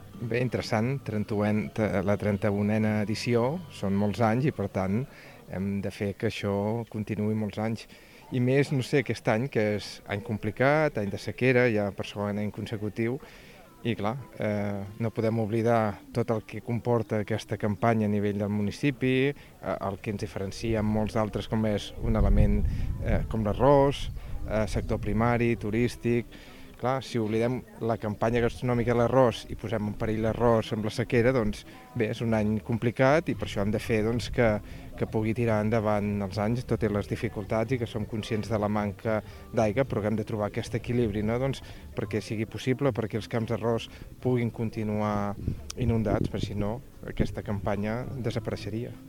L’Ajuntament de Pals a través d’aquesta presentació reivindicativa vol mostrar tot el seu suport als productors d’arròs amb l’objectiu de mostrar i buscar complicitats de manera conjunta per adoptar mesures per fer més eficient l’ús de l’aigua en el regadiu del territori i garantir que els arrossaires, una professió molt antiga i establerta al poble, puguin continuar exercint la seva activitat. Ho ha explicat l’alcalde del municipi, Carles Pi.